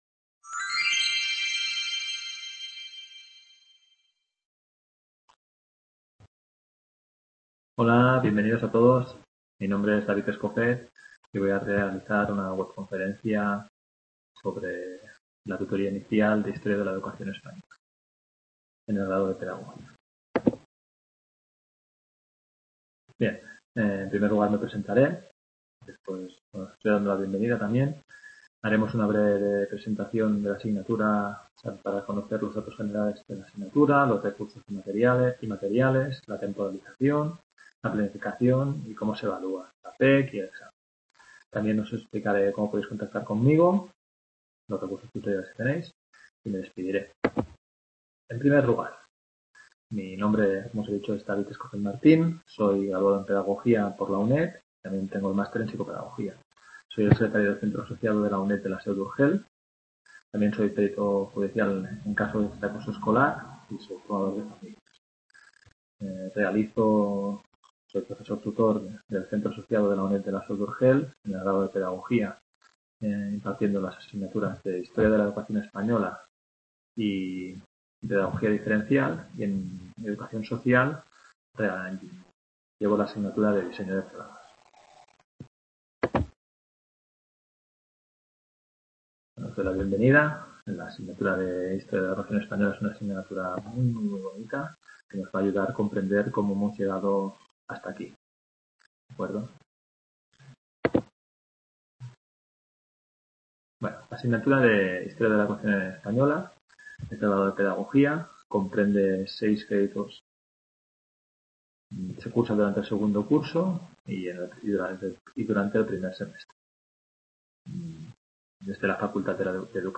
Historia de la Educación Española. Tutoría inicial
Video Clase